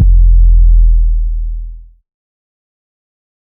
LexLuger808.wav